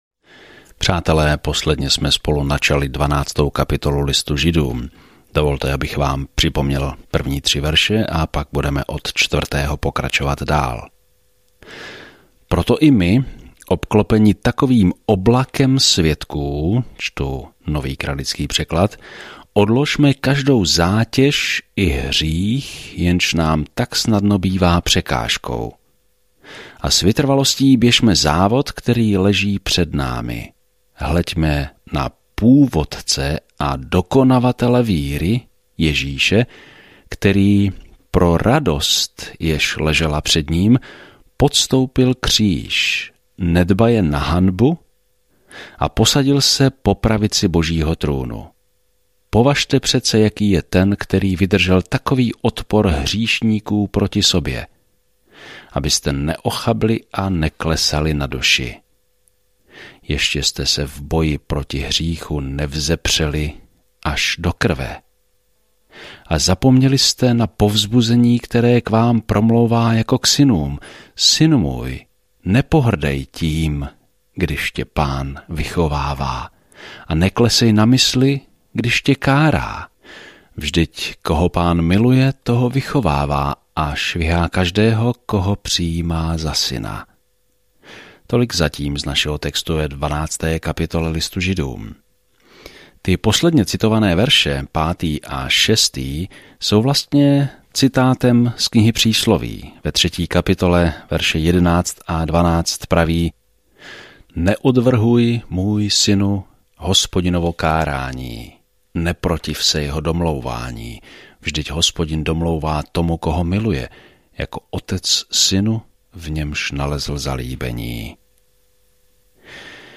Denně procházejte Hebrejcům, když posloucháte audiostudii a čtete vybrané verše z Božího slova.